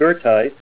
Say GOETHITE Help on Synonym: Synonym: Acicular iron ore   ICSD 28247   Limonite   Needle ironstone   PDF 29-713